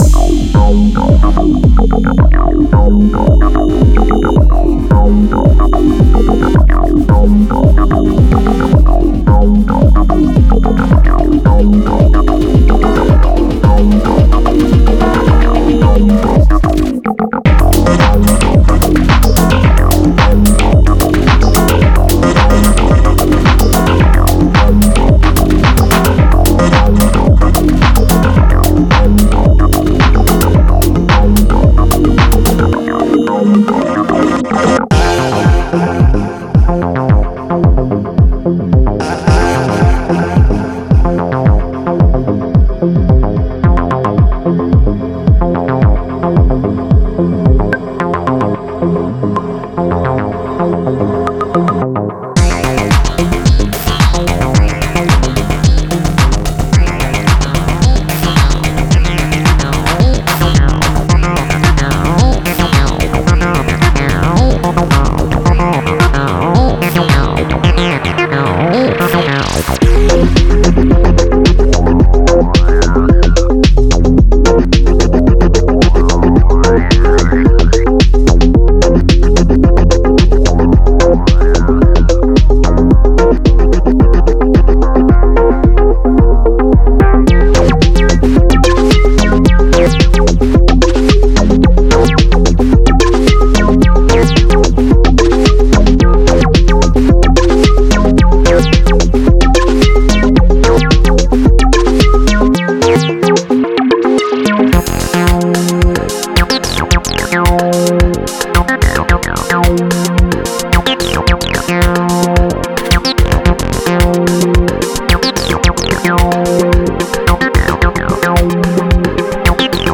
Genre:Acid House
テンポは110 BPM。
収録されているサウンドは、触感的で没入感があり、さりげなくサイケデリック。
デモサウンドはコチラ↓